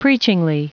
Prononciation du mot preachingly en anglais (fichier audio)
Prononciation du mot : preachingly